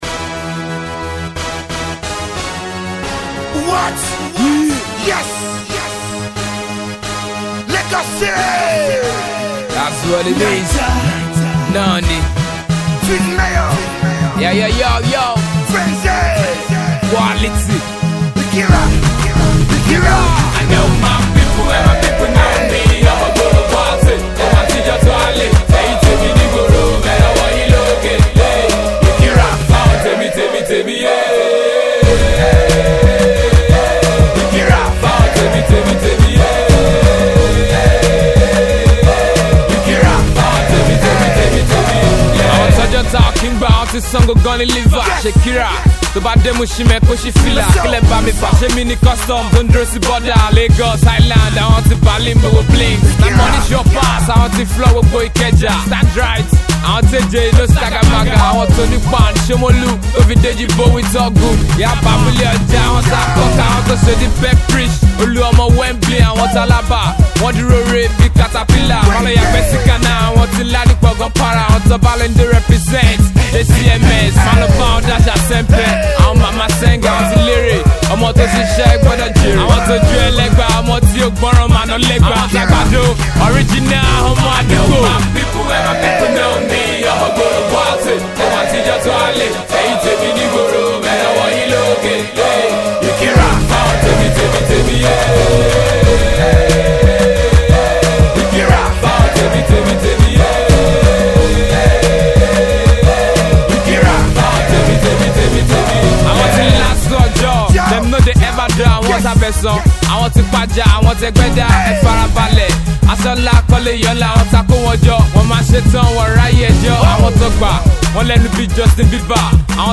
fluid rap style and street vocabulary